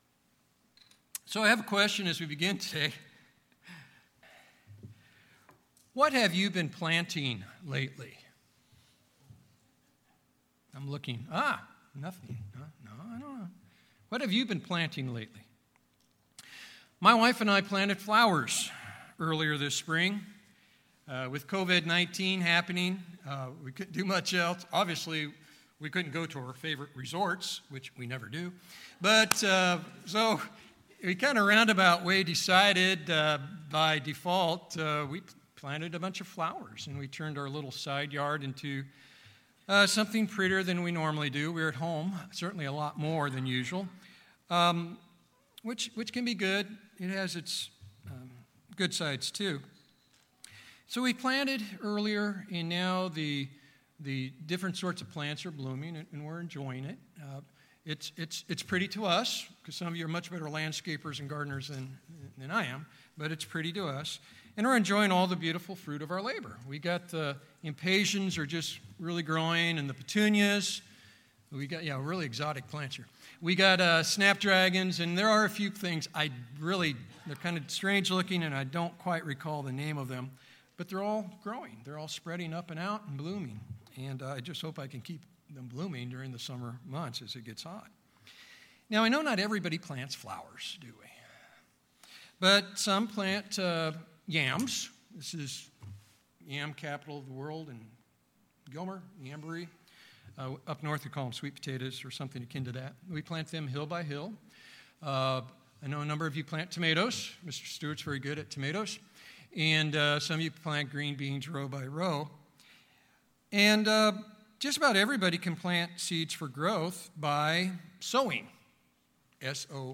In today's sermon we will consider these two ways of life and so be reminded to keep sowing to the Spirit.